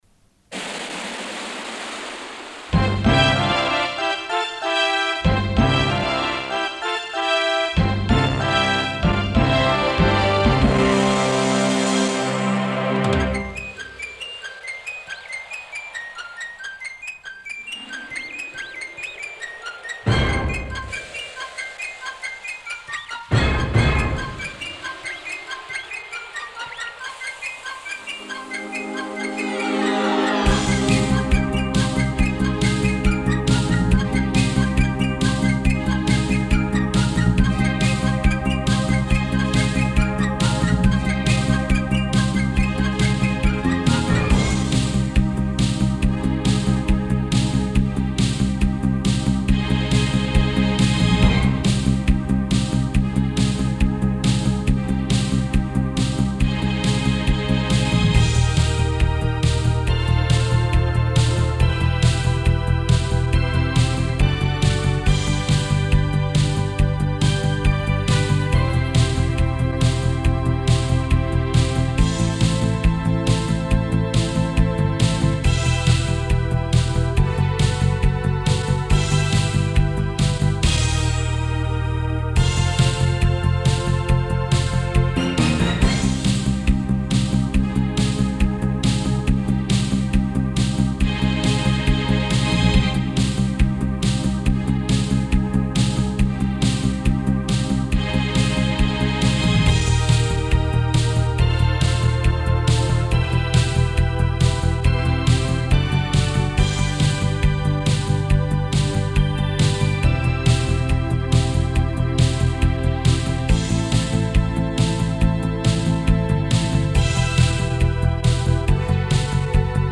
минусовка версия 226907